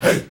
SouthSide Chant (29).wav